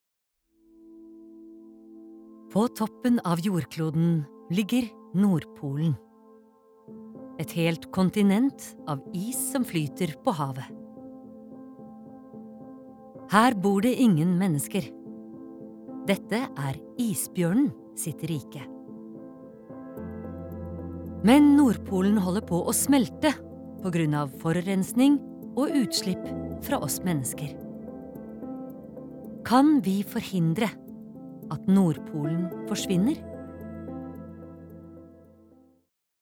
Documentary